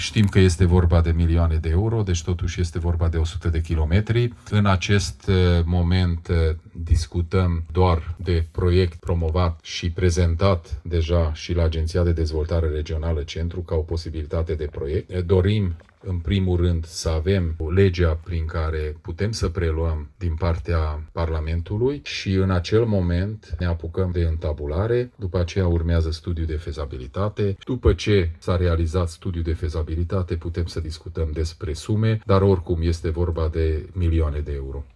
Președintele Consiliului Județean Mureș, Peter Ferenc, candidat din partea UDMR la un nou mandat de președinte, a declarat că proiectul ar putea fi realizat prin fonduri europene, inclusiv studiile necesare.